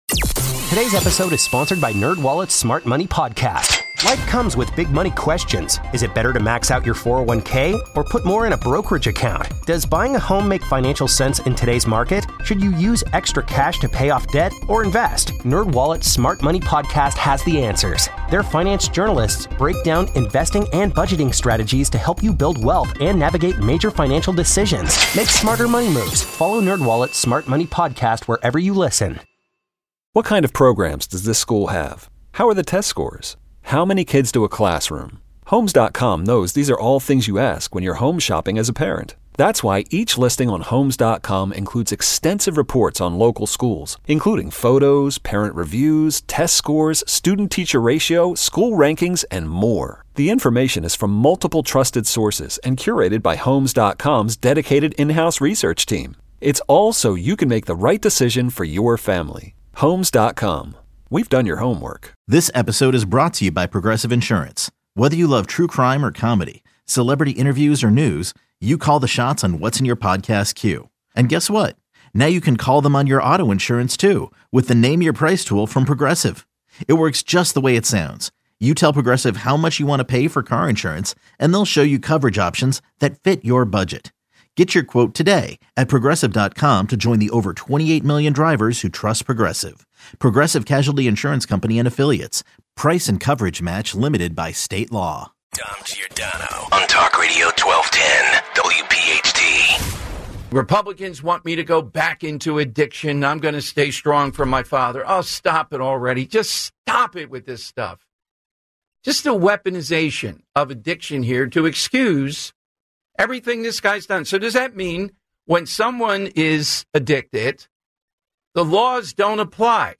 Then, Dave passes the phone over to Sen. Ernst, who explains why McCormick’s business background would be a huge benefit to her work she’s done with small businesses in Senate. Also, Senator Ernst previews tomorrow nights’ Congressional Baseball Game, revealing who the Republican pitchers will be and noting who the best on the team are as they look to win their 4th straight game.